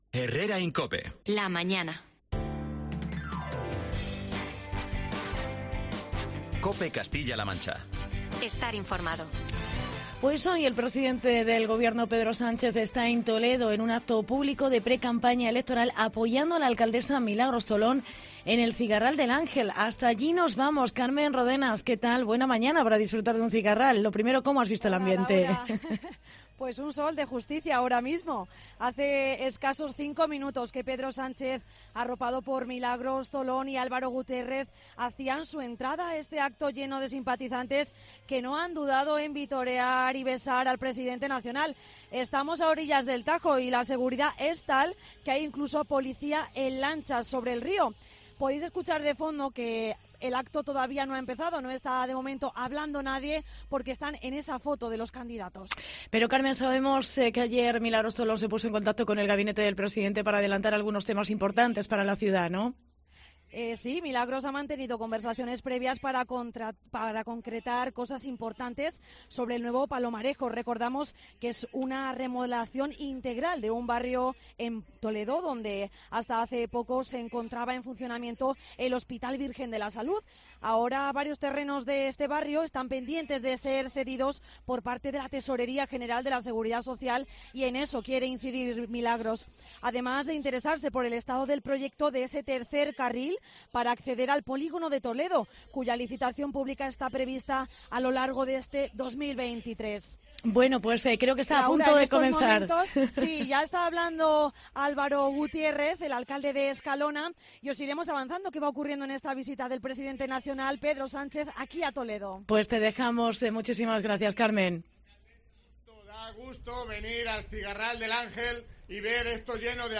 Directo desde el Cigarral del Ángel ante la visita de Pedro Sánchez a Toledo